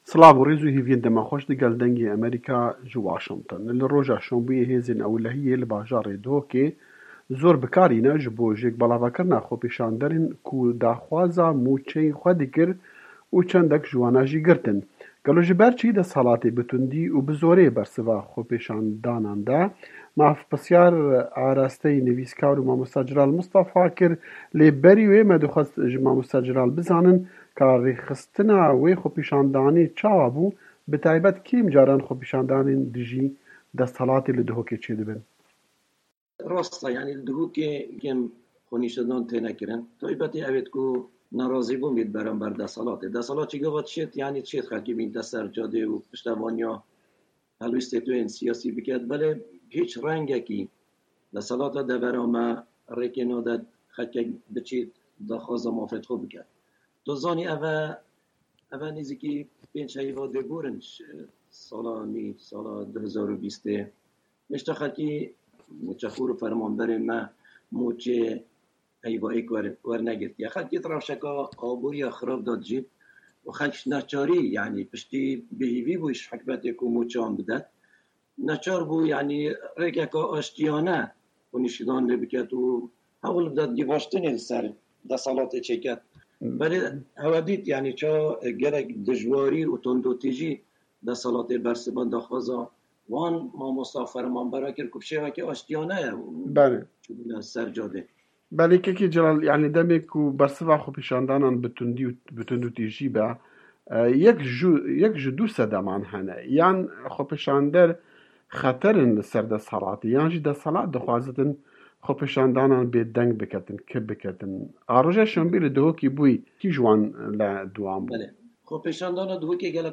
Herêmên Kurdan - Hevpeyvîn